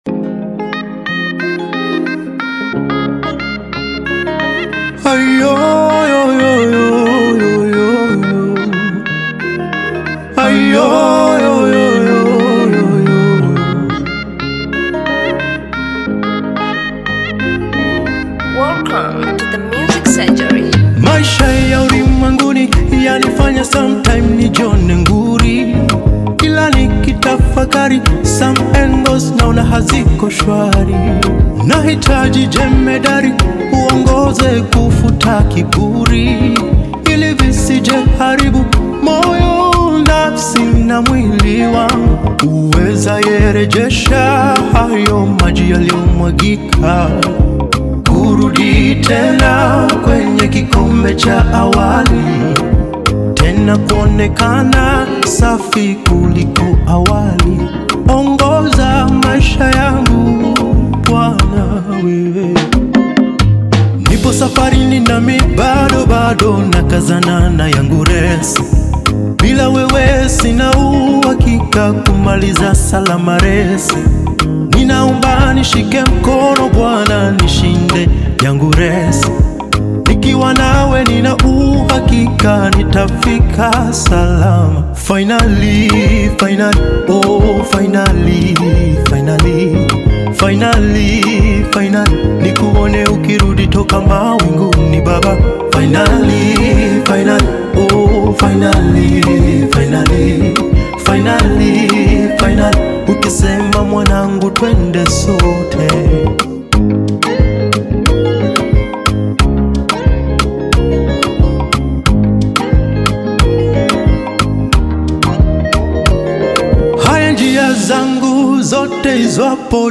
Gospel music track
Bongo Flava